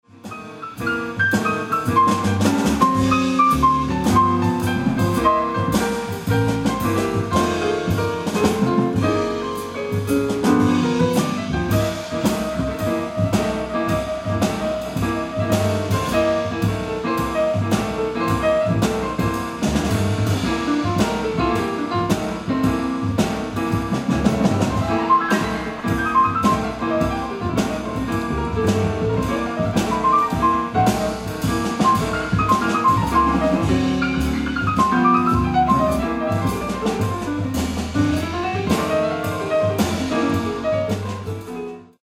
ライブ・アット・リオン、フランス 07/23/2010
※試聴用に実際より音質を落としています。